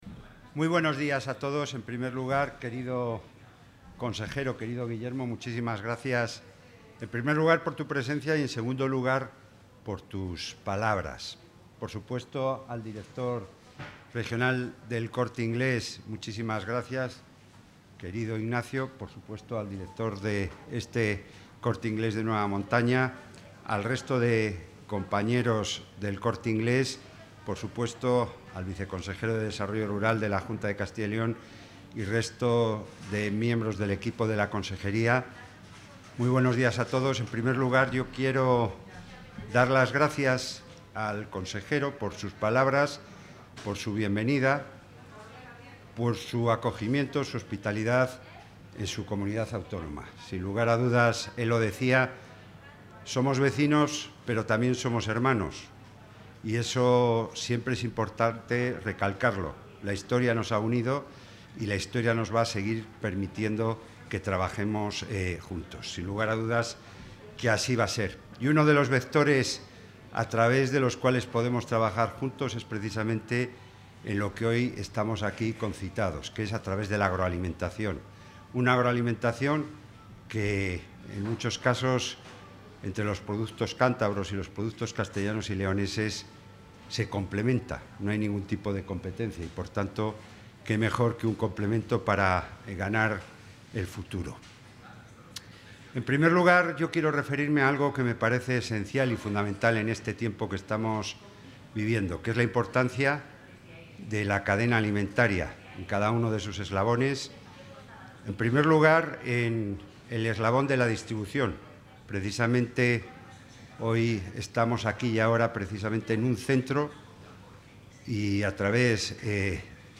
Intervención del consejero de Agricultura, Ganadería y Desarrollo Rural.